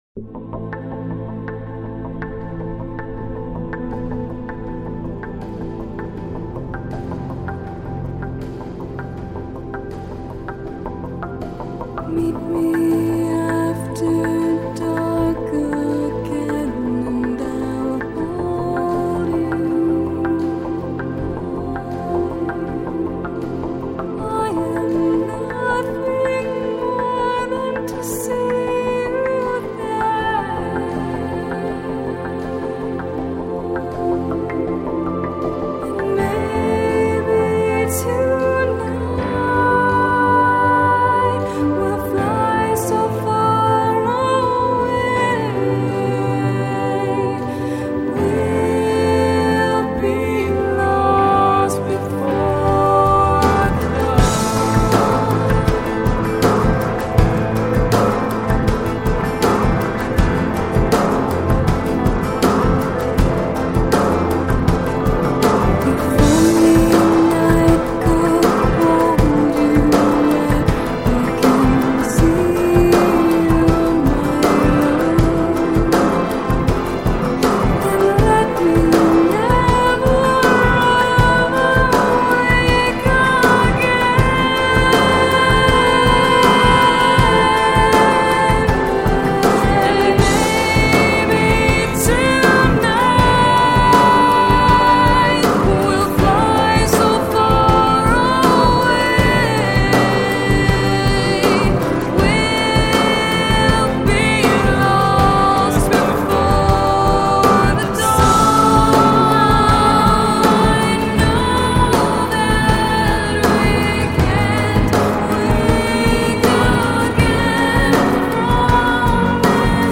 Жанр: Gothic Metal